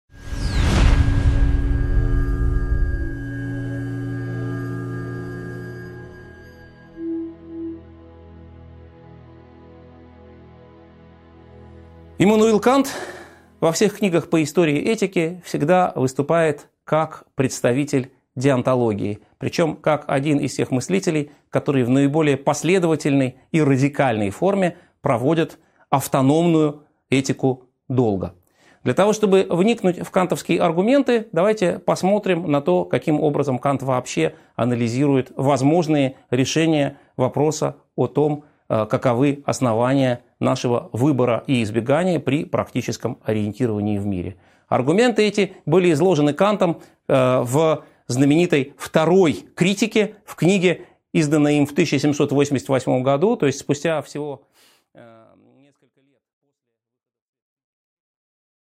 Аудиокнига 11.8 Деонтология | Библиотека аудиокниг